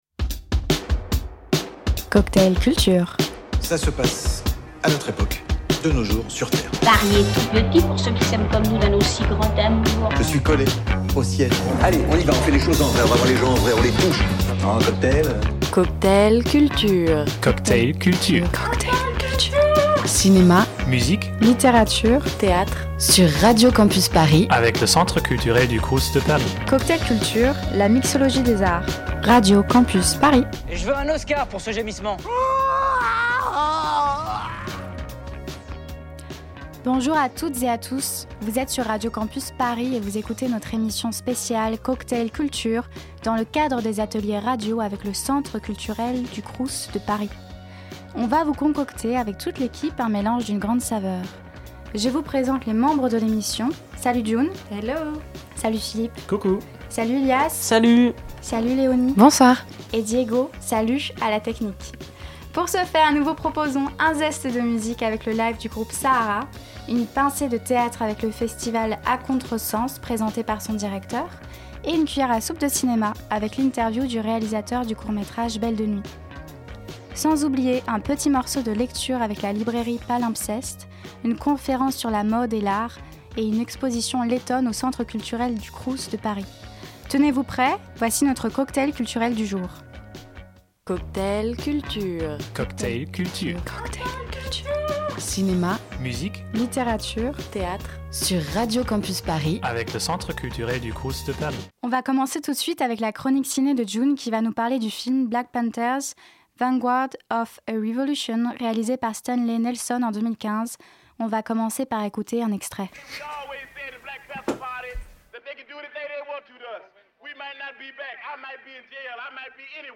Le Tour des Facs de Radio Campus Paris pose ses valises au Centre culturel du CROUS de Paris pour la 3ème année.